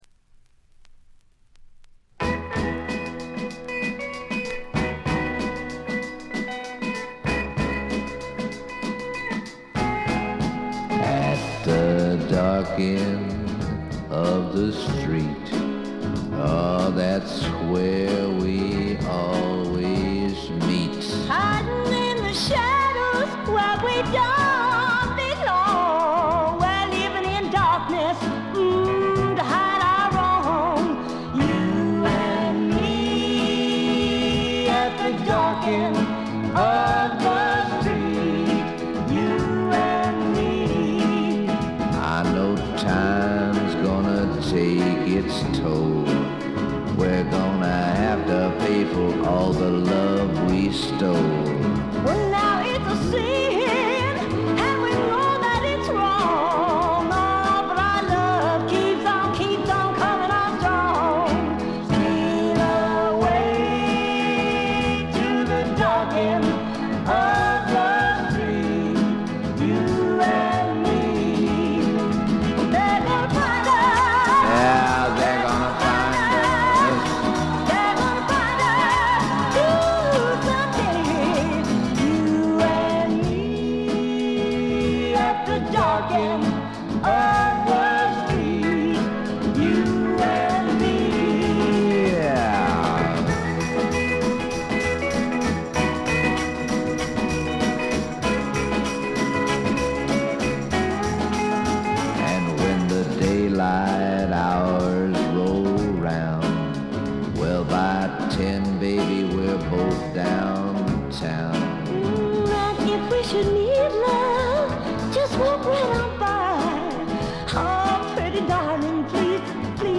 軽微なバックグラウンドノイズ。散発的なプツ音が2-3回程度。
試聴曲は現品からの取り込み音源です。